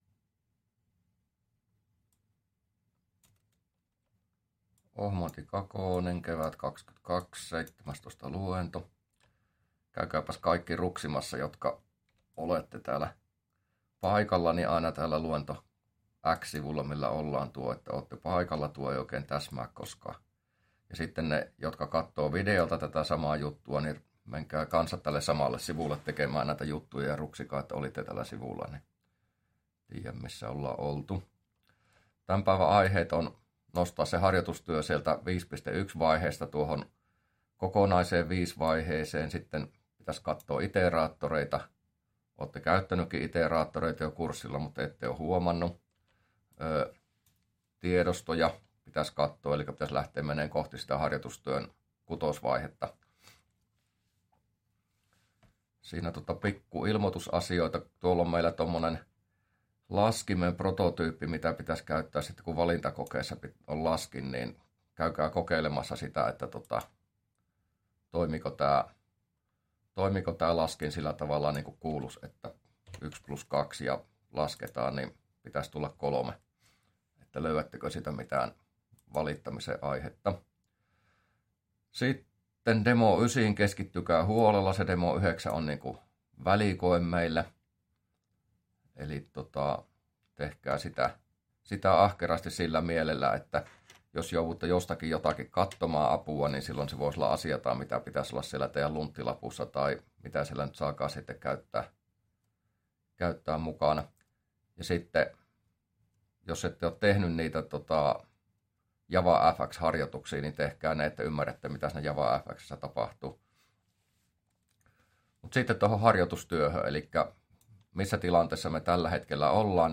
luento17a